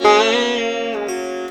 SITAR LINE53.wav